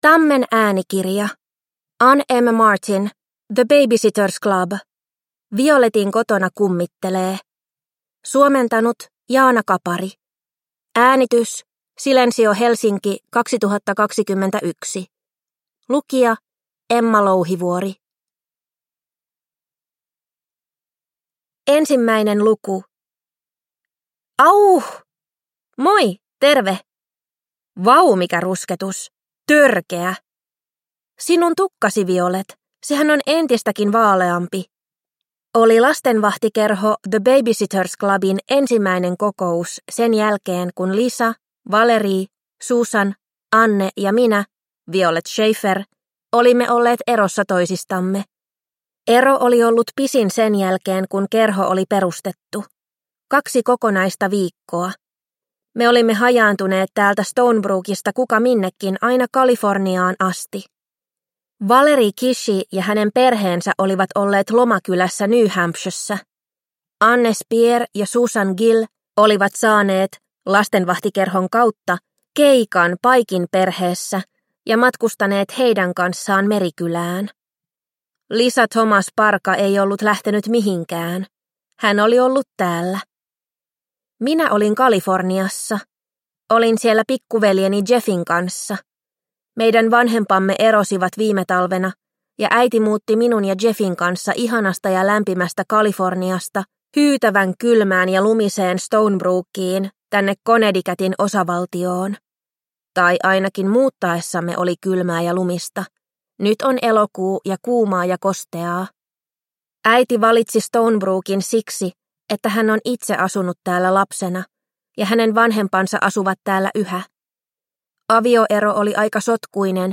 The Baby-Sitters Club. Violetin kotona kummittelee – Ljudbok – Laddas ner